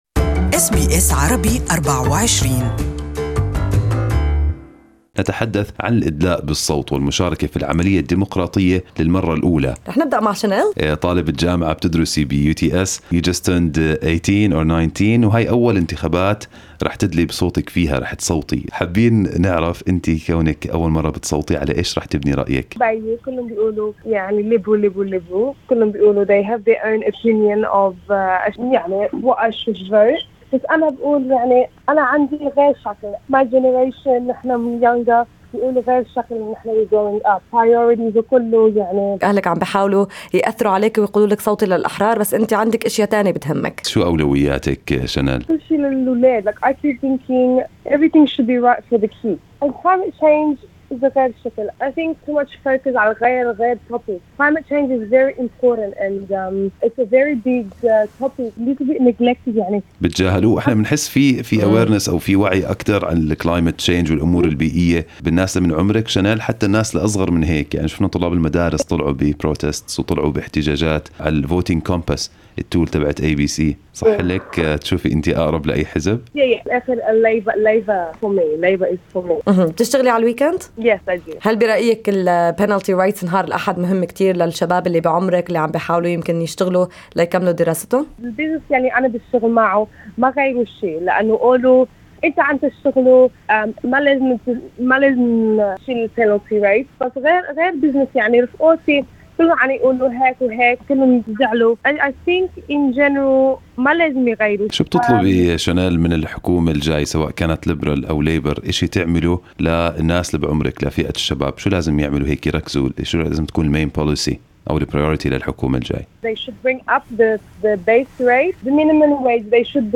First time Arab Australian voter talks about her main concerns